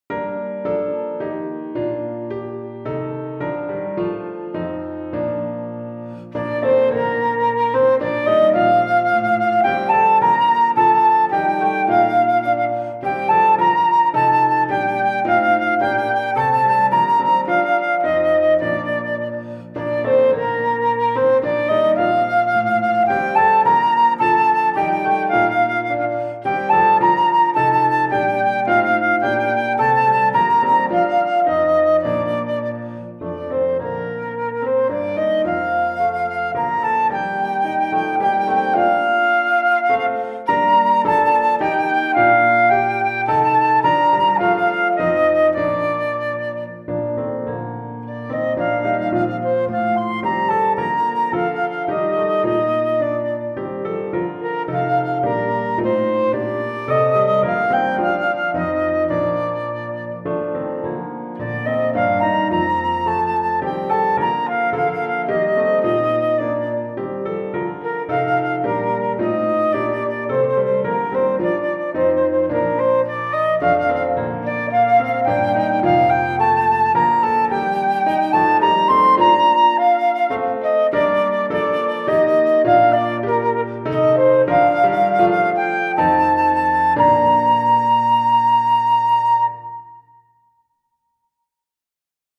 Flûte Traversière et Piano